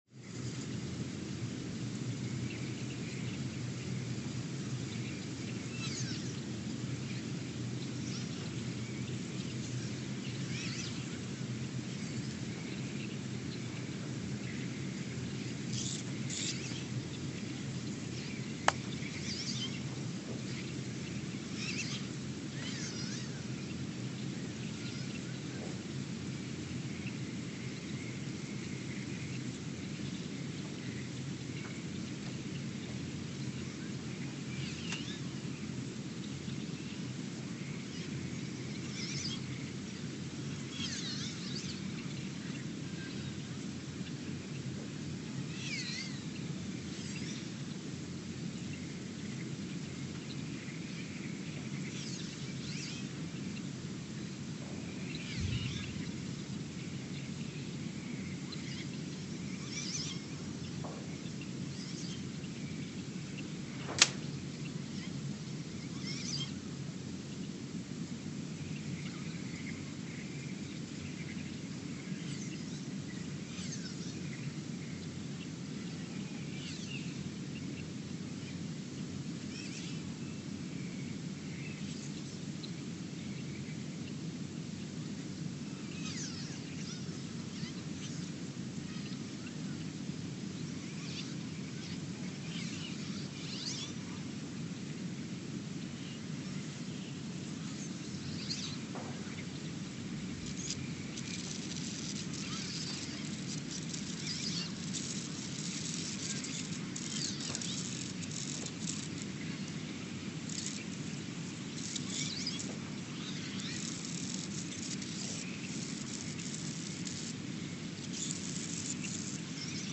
Ulaanbaatar, Mongolia (seismic) archived on September 24, 2023
No events.
Sensor : STS-1V/VBB
Speedup : ×900 (transposed up about 10 octaves)
Loop duration (audio) : 03:12 (stereo)
SoX post-processing : highpass -2 90 highpass -2 90